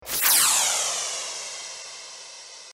Звуки вспышки света
В коллекции представлены как резкие импульсные звуки, так и мягкие световые эффекты.